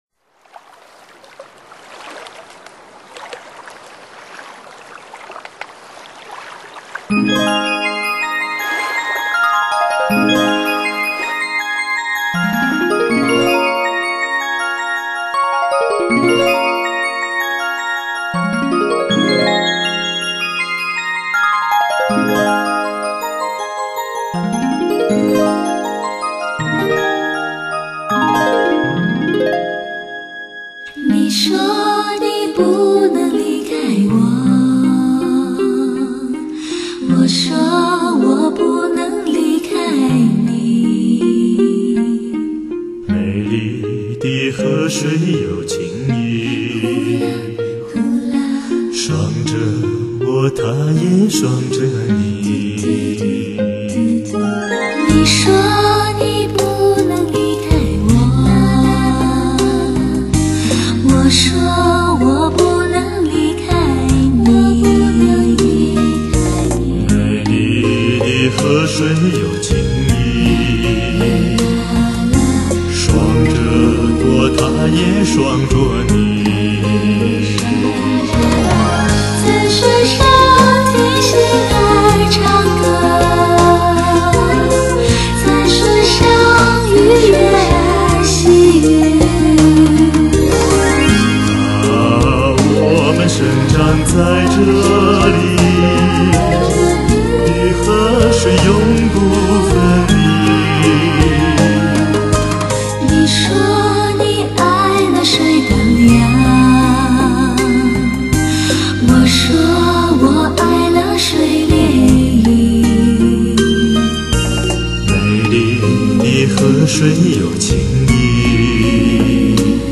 如此经典的旋律，如此新鲜的诠释。